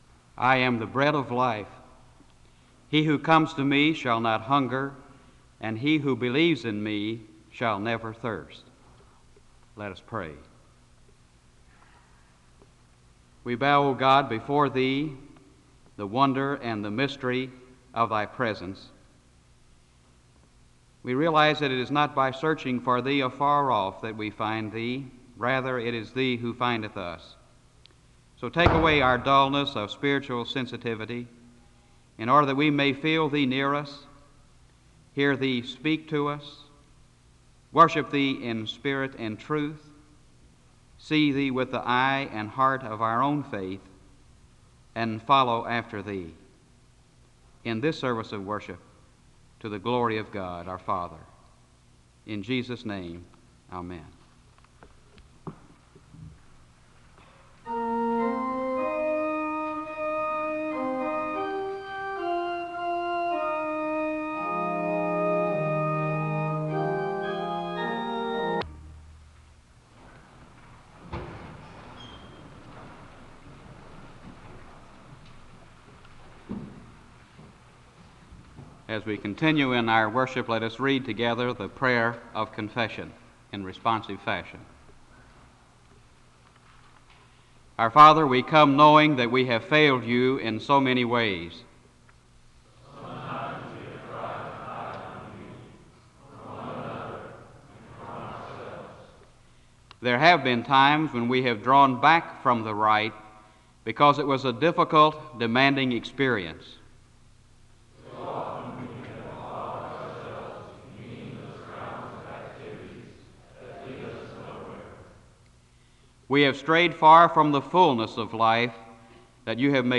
Download .mp3 Description The service opens with a word of prayer (00:00-00:54). The speaker and audience read from the prayer of confession, and there is a time of private confession followed by a public word of prayer (00:55-03:37). The choir leads in a song of worship (03:38-07:01).
The service ends with a prolonged period of silence (32:11-36:53).